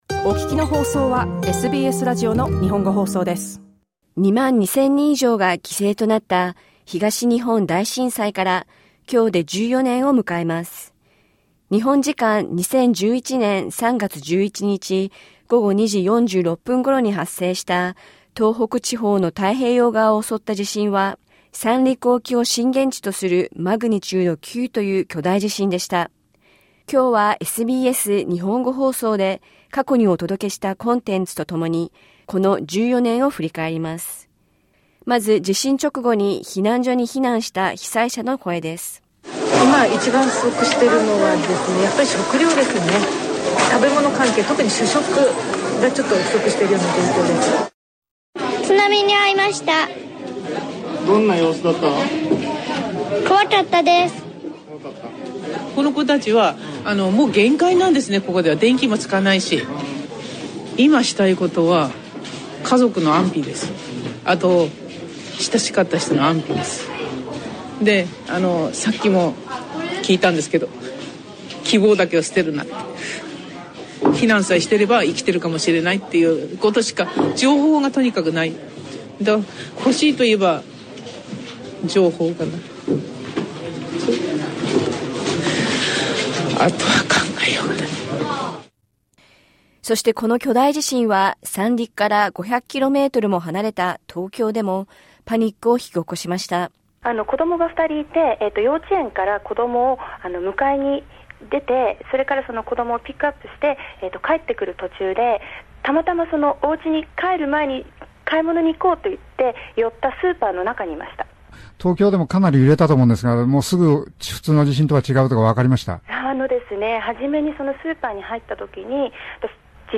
当時の被災者の声、震災を風化させないために語り部活動を続ける学生、そして10年にわたり被災者支援を行ってきたJCSレインボープロジェクトの取り組みをお届けします。